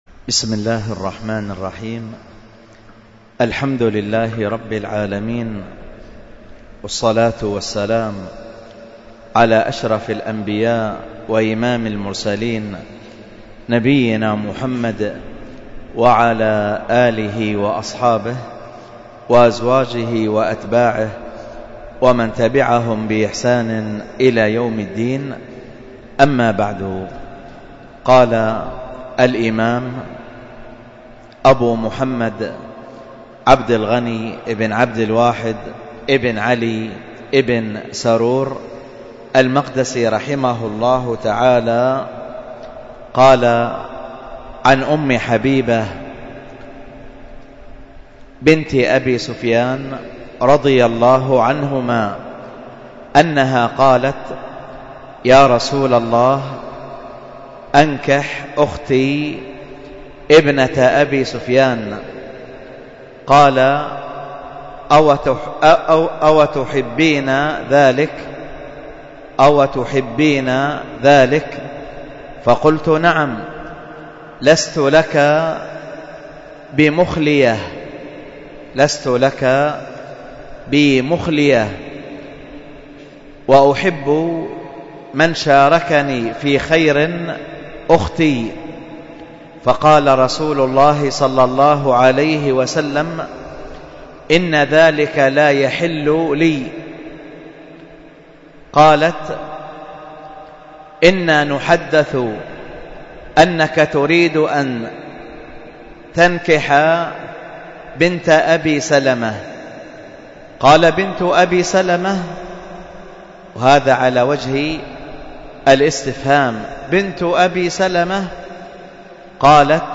الدرس في كتاب الزكاة 1، ألقاها